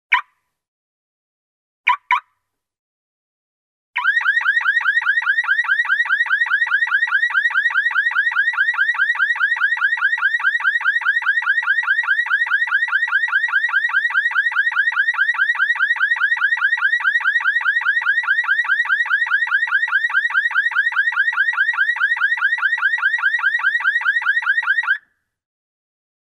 На этой странице собраны различные звуки автомобильных сигнализаций – от резких гудков до прерывистых тревожных сигналов.
Звук сигнализации автомобиля